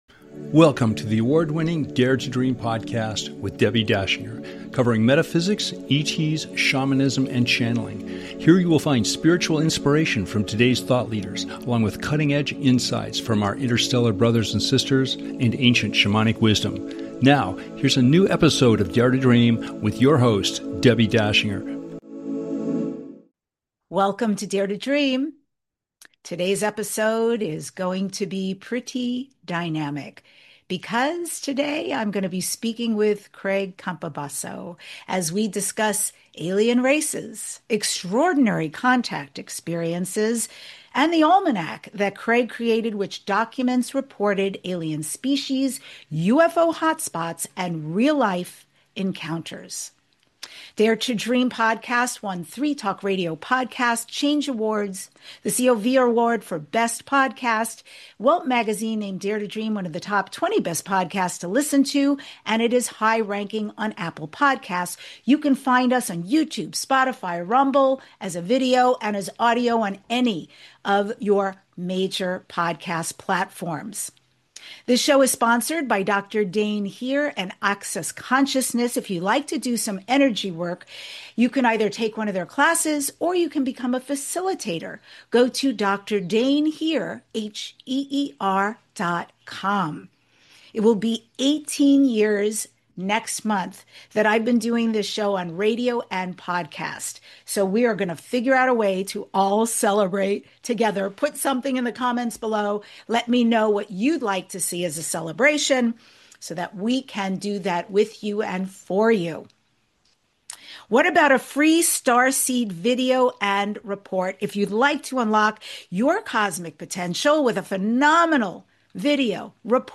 This isn't just an interview—it's a galactic revelation.